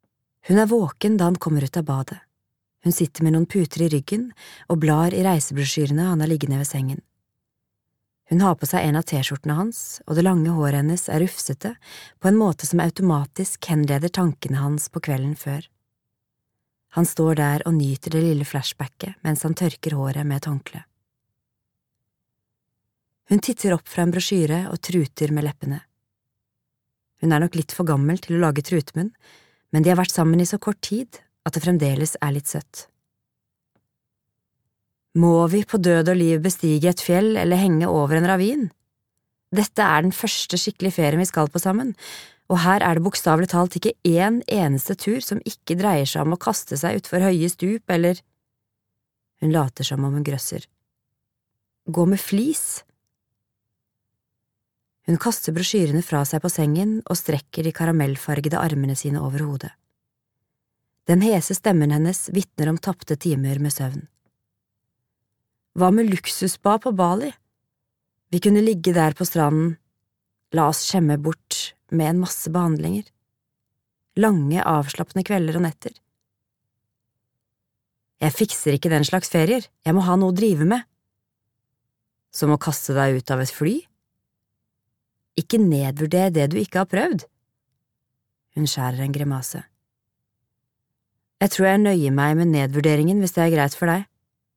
Et helt halvt år (lydbok) av Jojo Moyes